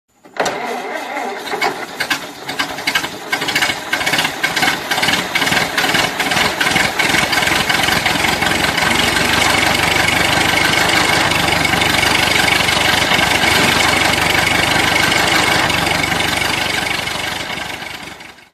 Car Startup